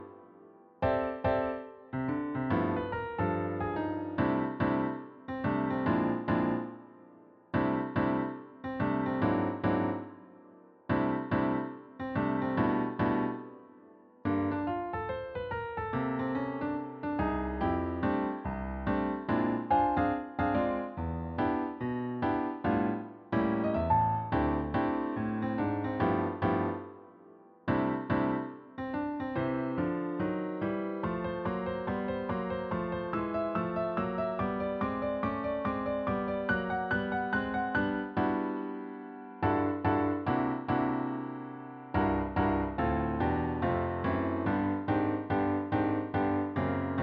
Women Always Do What Must Be Done – Piano TracksDownload